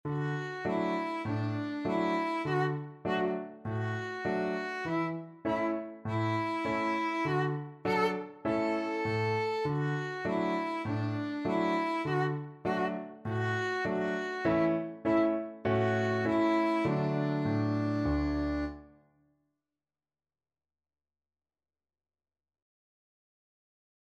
Violin
4/4 (View more 4/4 Music)
Allegretto
Traditional (View more Traditional Violin Music)